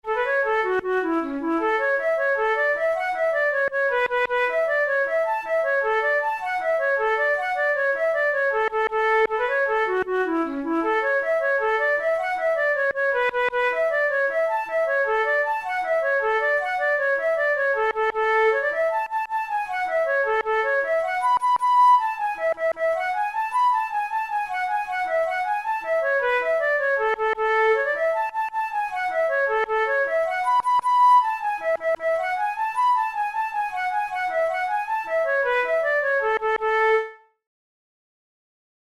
InstrumentationFlute solo
KeyA major
Time signature6/8
Tempo104 BPM
Jigs, Traditional/Folk
Traditional Irish jig